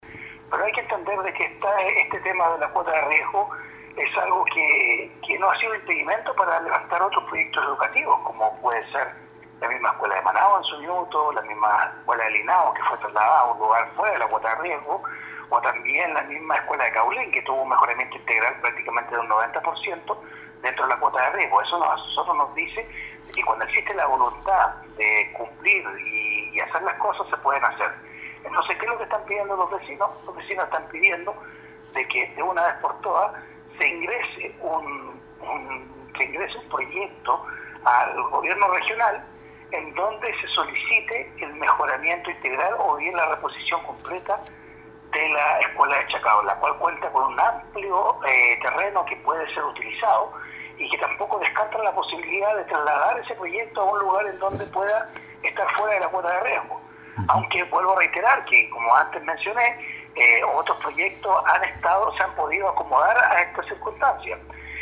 04-CONCEJAL-ANDRES-IBANEZ-2.mp3